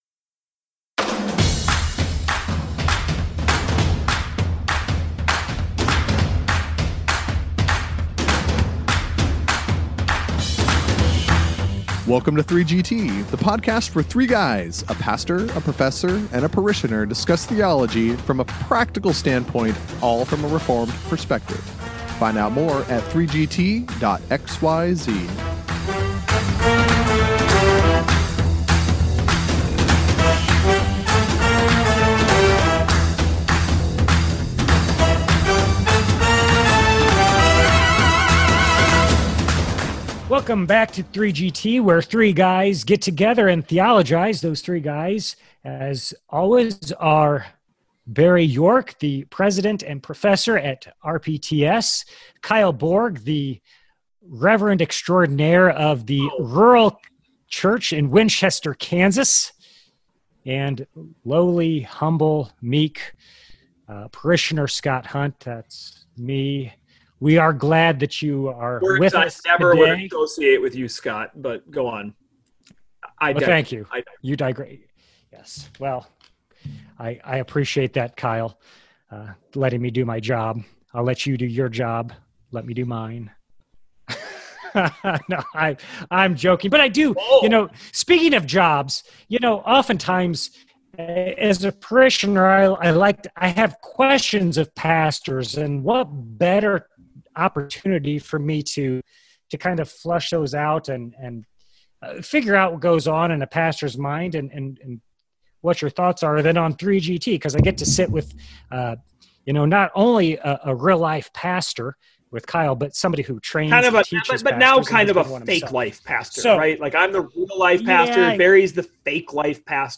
After the boys banter a bit, they get down to the subject at hand. The parishioner wants the preacher and preaching teacher to tell him what prophetic preaching is.